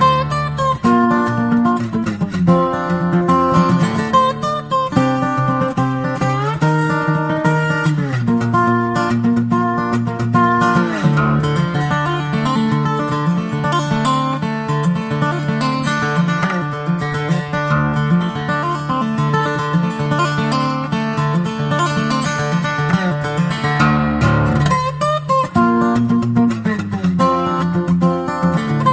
Category: Guitar Ringtones